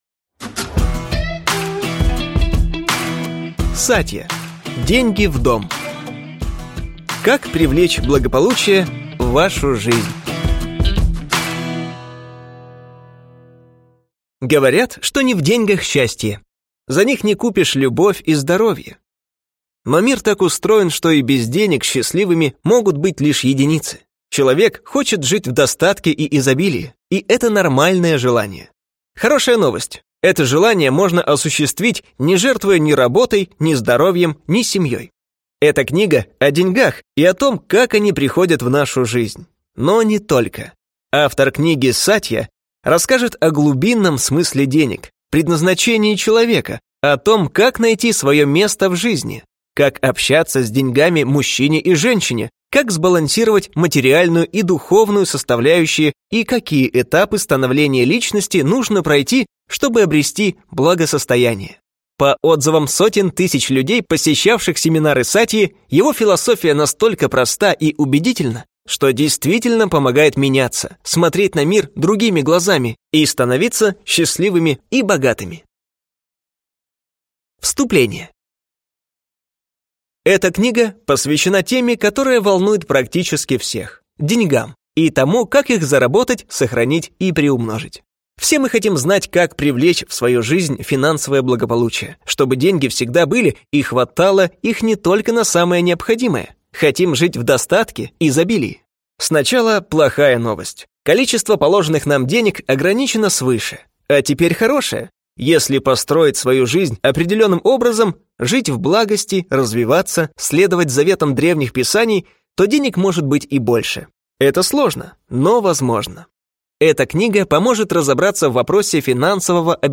Аудиокнига Деньги в дом. Как привлечь благополучие в вашу жизнь | Библиотека аудиокниг